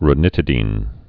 (rə-nĭtĭ-dēn)